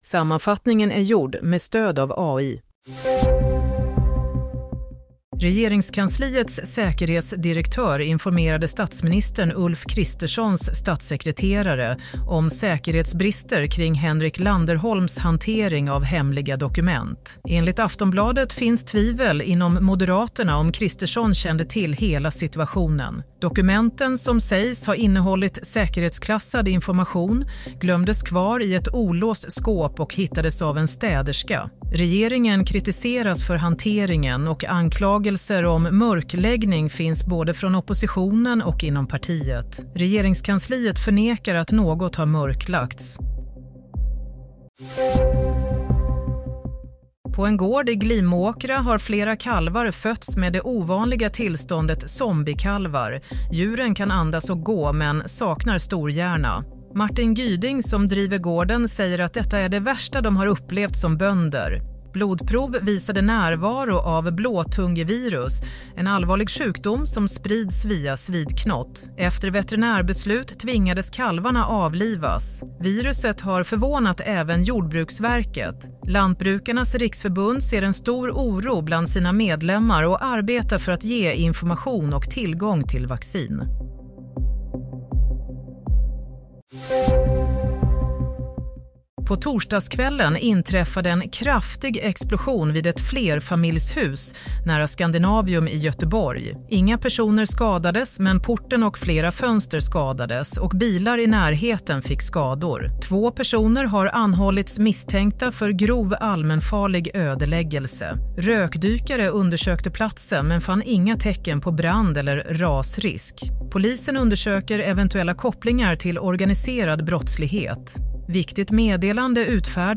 Nyhetssammanfattning - 14 mars 07:00